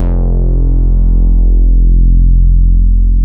MODULAR F2F.wav